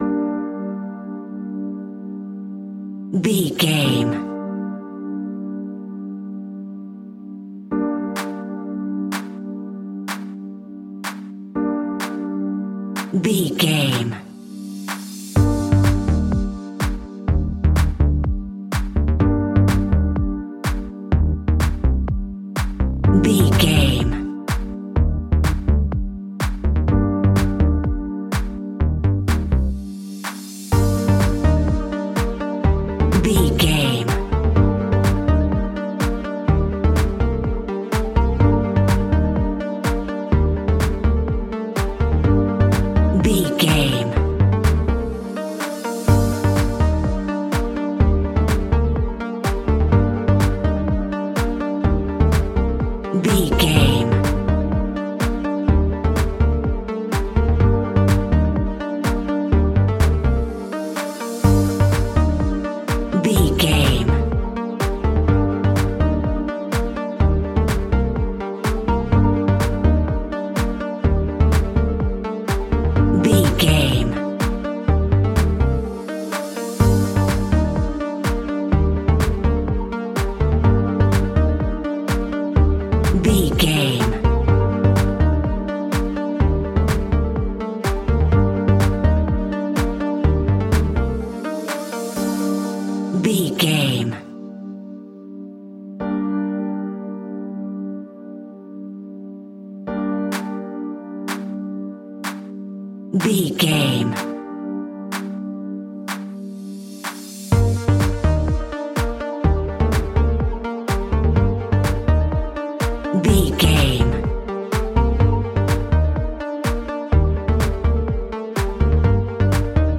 Aeolian/Minor
groovy
uplifting
driving
energetic
repetitive
drum machine
synthesiser
bass guitar
funky house
nu disco
upbeat
funky guitar
wah clavinet
fender rhodes
synth bass